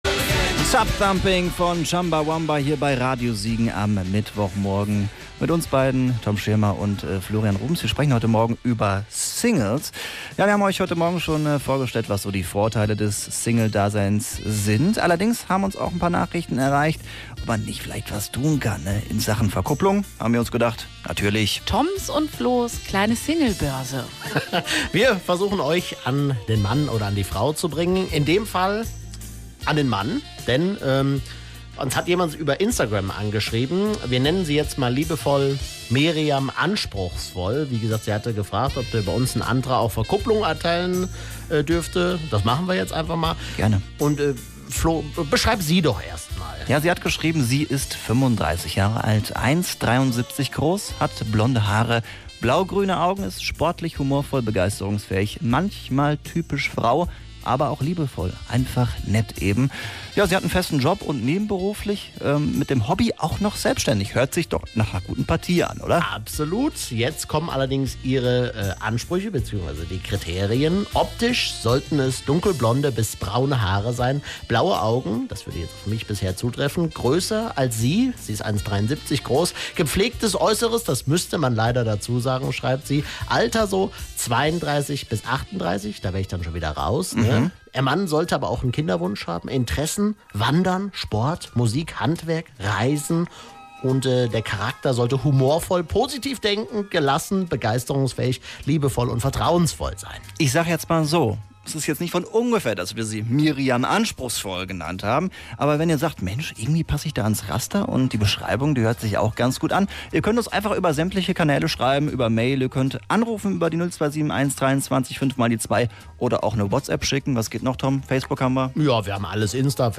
Radio Siegen am Morgen wurde kurzerhand zur Kupplungs-Show